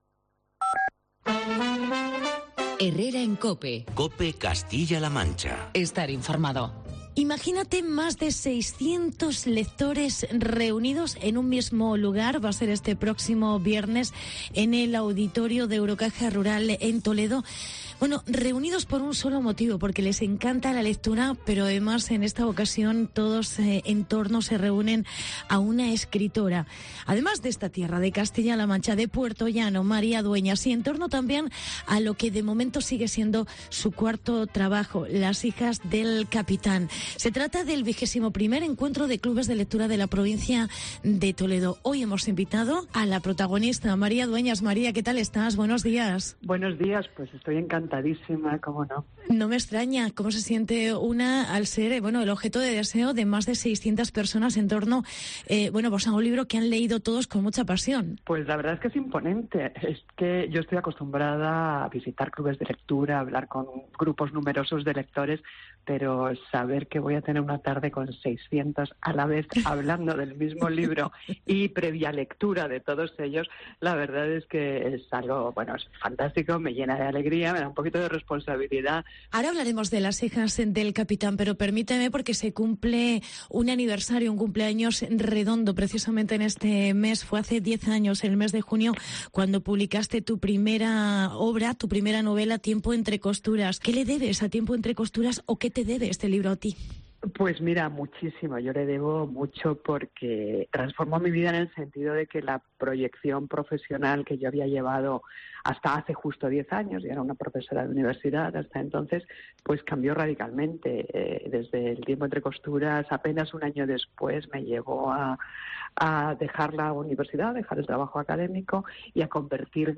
María Dueñas se reúne con 600 lectores de su libro "Las hijas del capitán". Entrevista